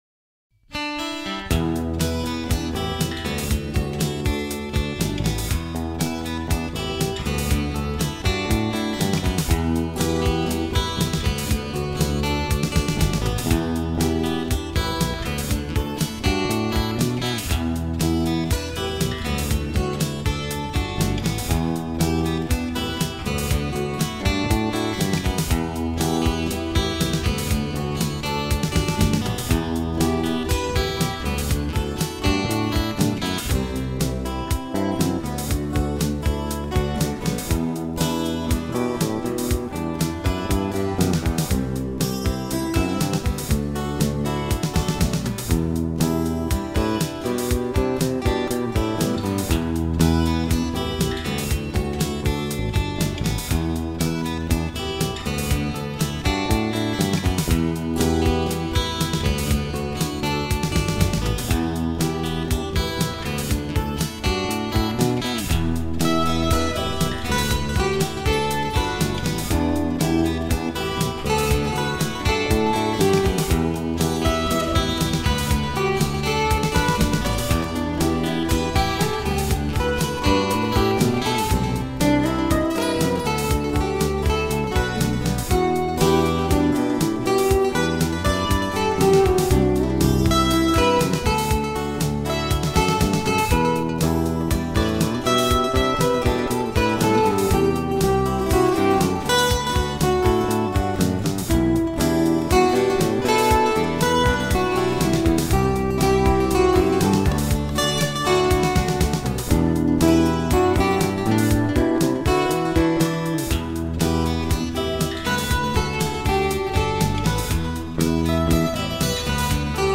| Em | D7 |
G/Em 118bpm